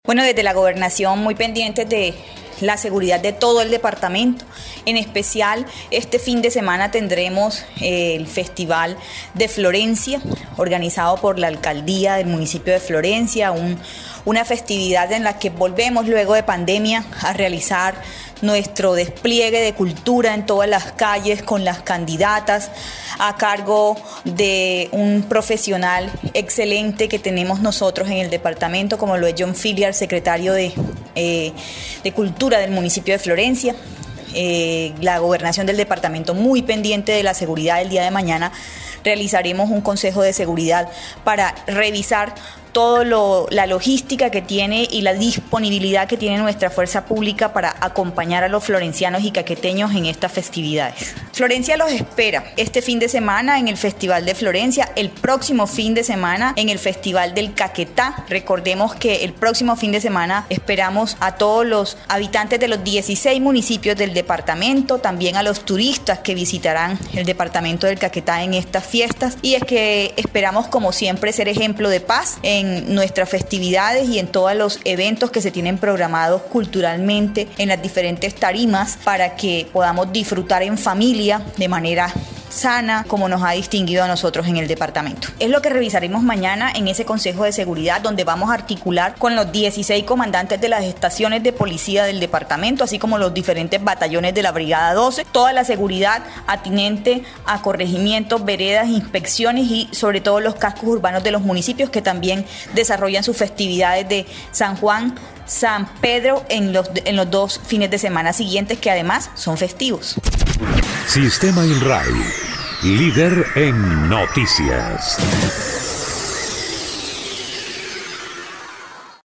Sandra Rodríguez Pretelt, secretaria de gobierno del departamento, explicó que, para tal fin, este jueves se llevará a cabo un consejo de seguridad con los comandantes de estación de policía municipal, para adoptar las acciones que serán adoptadas en lo que serán las fiestas culturales de los siguientes fines de semana.
La funcionaria hizo un llamado a la ciudadanía, urbana y rural, para que esta disfrute de las fiestas tradicionales de manera responsable sin excesos en el consumo de licor y en familia, con el ánimo de disminuir las acciones que puedan generar hechos que lamentar en medio de las celebraciones.